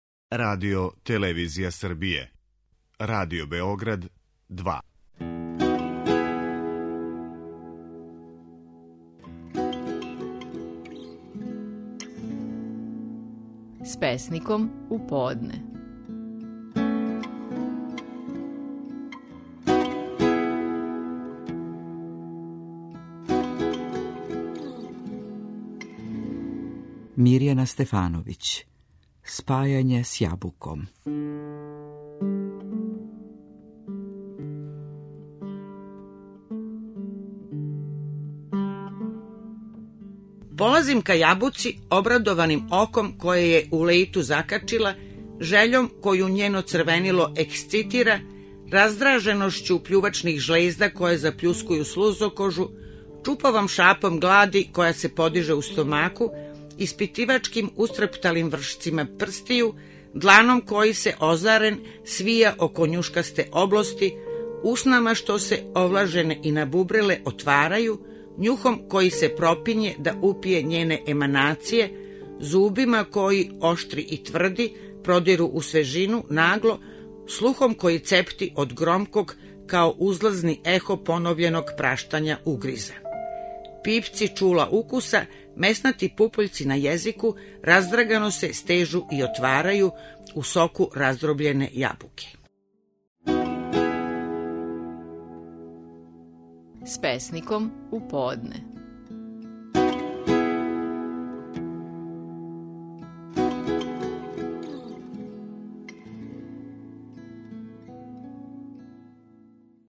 Стихови наших најпознатијих песника, у интерпретацији аутора.
Мирјана Стефановић говори песму „Спајање с јабуком".